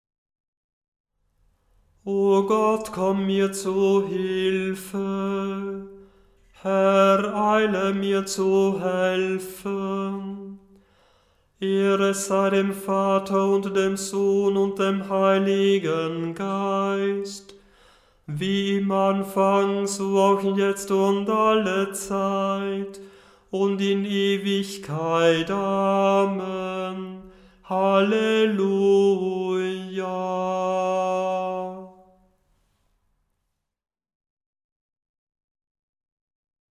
Beim Stundengebet übernimmt nach der Einleitung ein Kantor/eine Kantorin das Anstimmen der Gesänge.
Beim Stundengebet selber wird der Ruf natürlich nur einmal gesungen, die mehrmaligen Wiederholunge mögen das Üben erleichtern.
O Gott, komm mir zu Hilfe, Gl 627,1 897 KB Erster Ton g, wie notiert